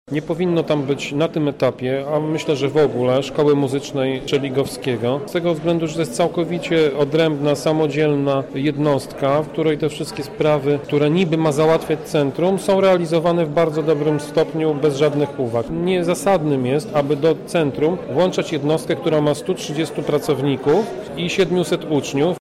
-mówi Tomasz Pitucha, radny miasta.